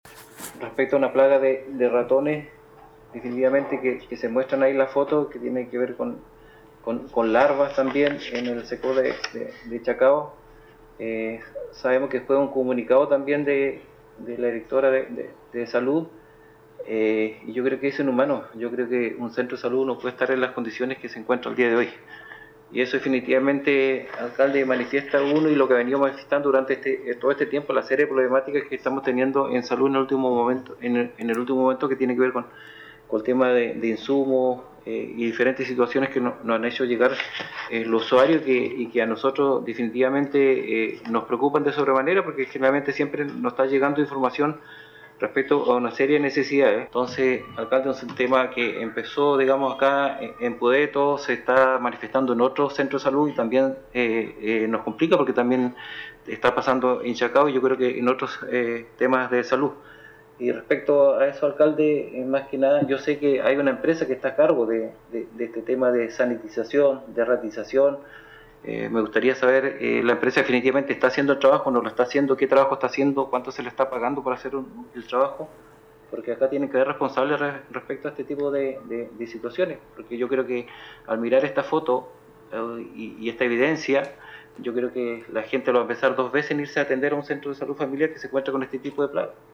Esto fue lo señalado por el concejal Alex Muñoz respecto de esta situación que afecta al Cecof de Chacao.
12-CONCEJAL-ALEX-MUNOZ.mp3